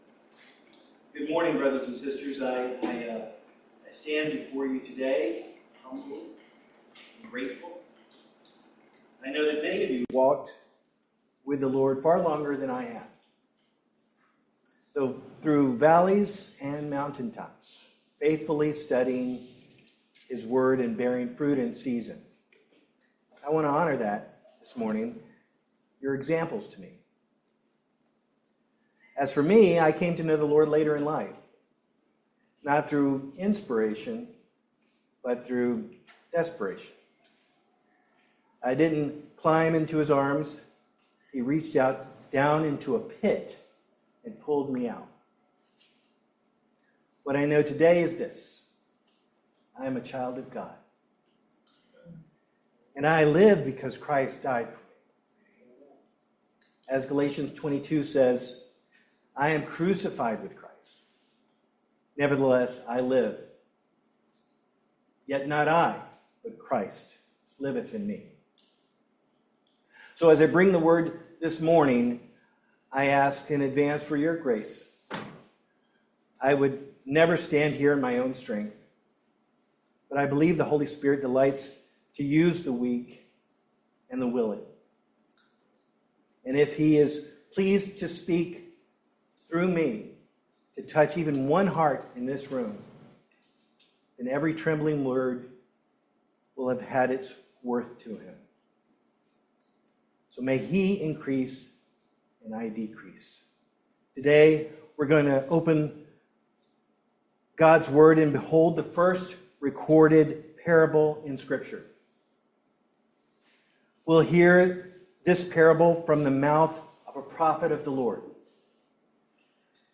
2 Samuel 12 Service Type: Family Bible Hour A journey through David’s fall